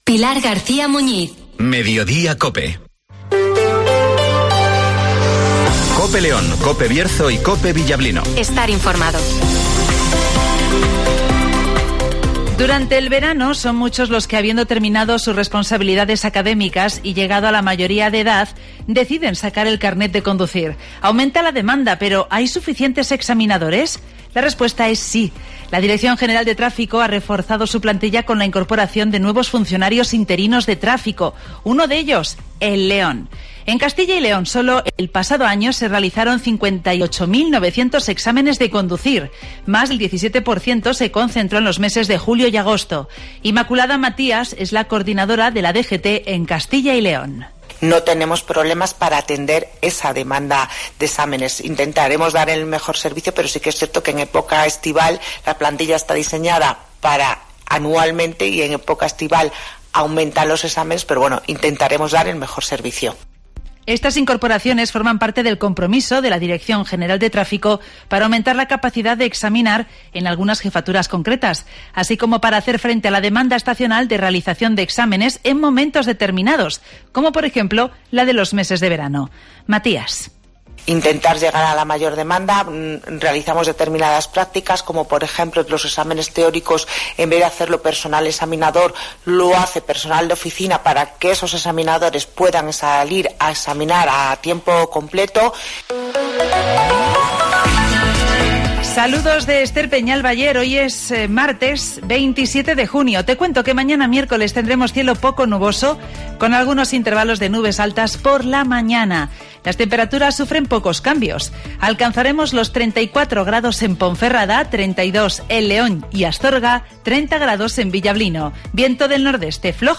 Escucha aquí las noticias con las voces de los protagonistas.